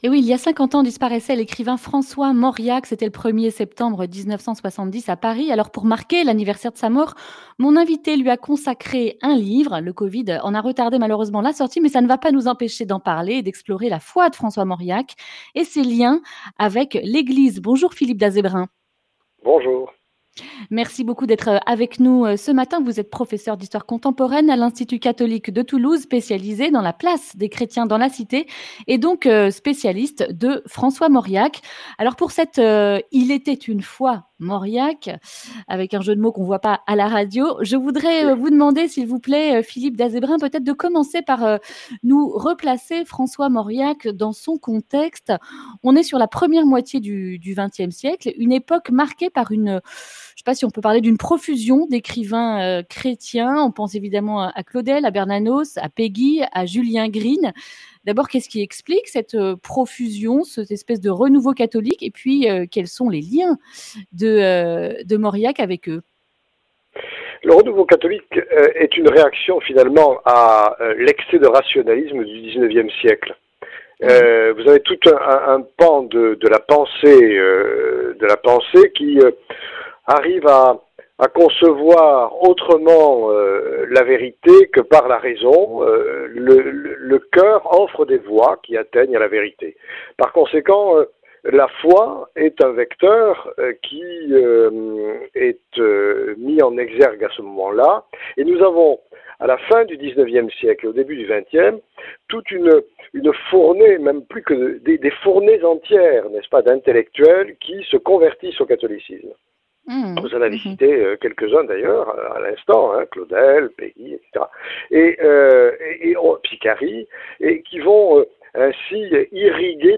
Accueil \ Emissions \ Information \ Régionale \ Le grand entretien \ Il était une foi...